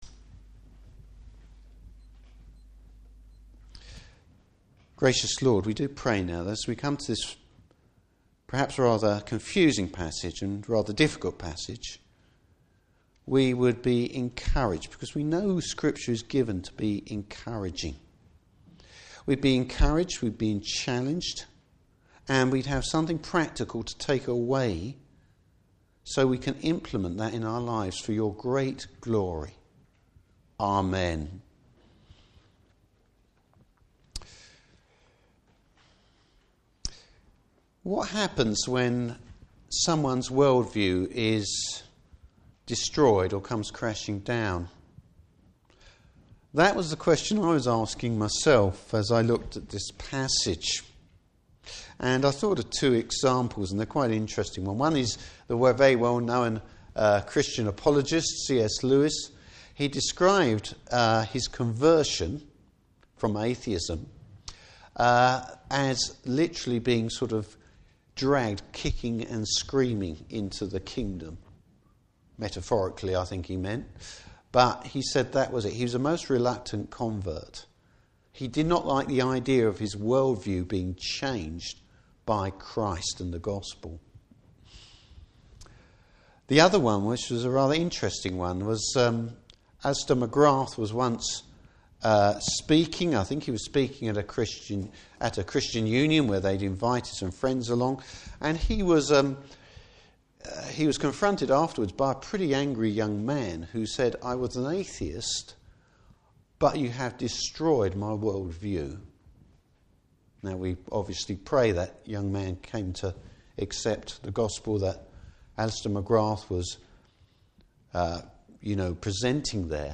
Service Type: Morning Service Why Jesus’ words will not pass away.